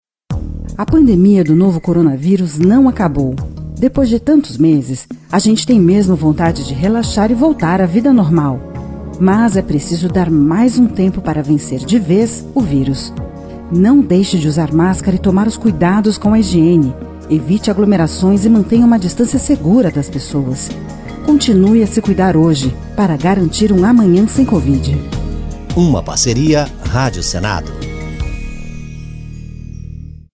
E a Rádio Senado produziu uma nova campanha de utilidade pública sobre a necessidade de a população continuar se protegendo. São quatro spots, de 30 segundos cada, alertando que falta pouco para vencermos o vírus e que ainda é preciso cuidado.